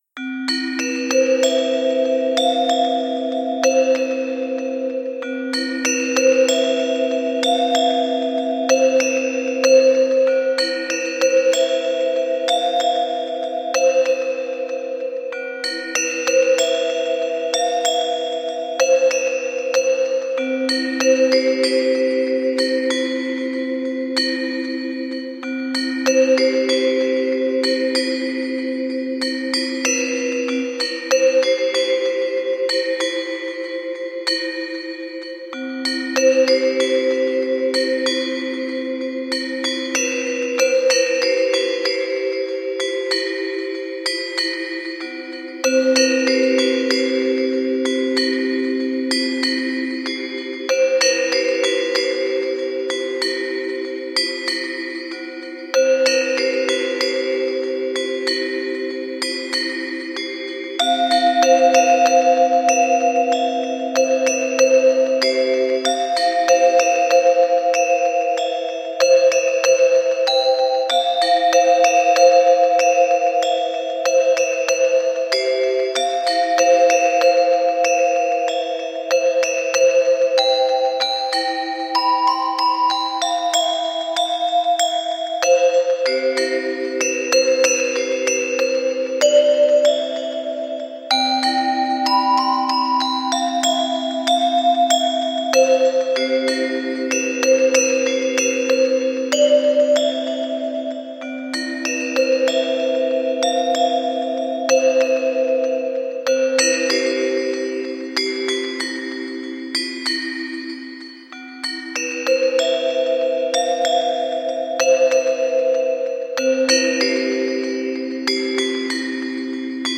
バリ風の雰囲気の曲です。【BPM95】